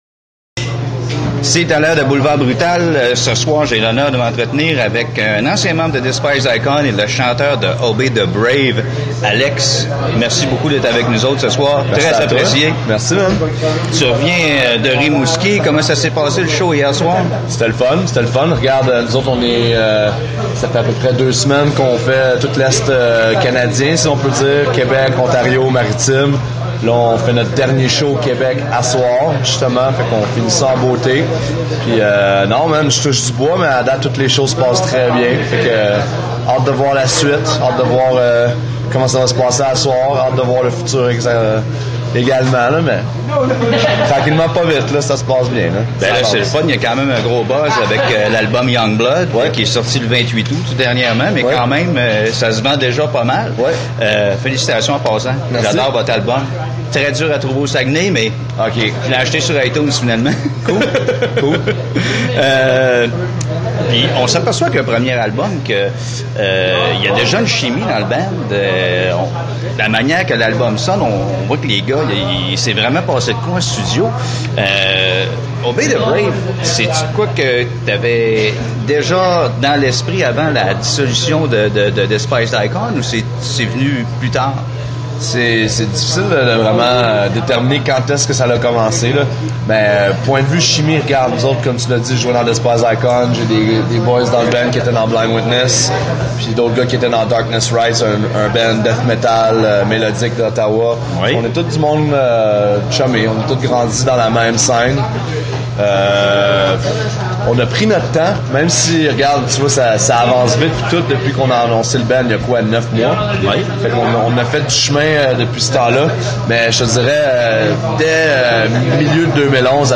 entrevue-otb1.mp3